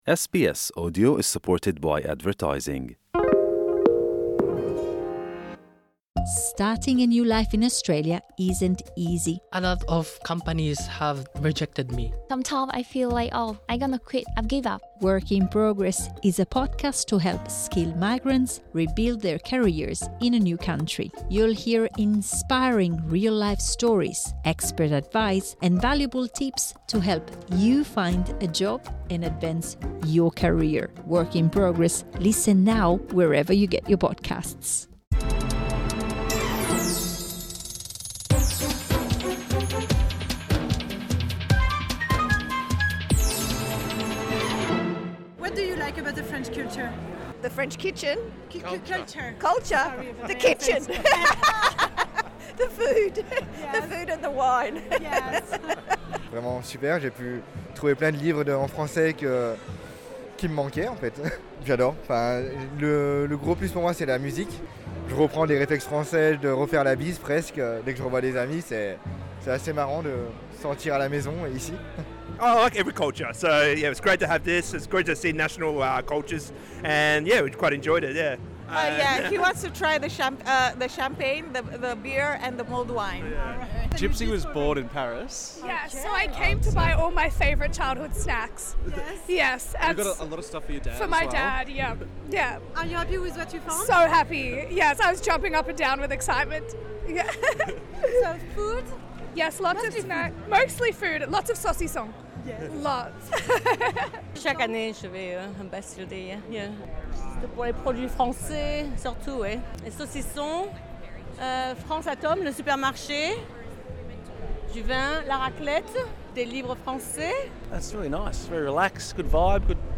Voxpop dans les allées du Queen Victoria Market pour le Bastille day Festival de Melbourne.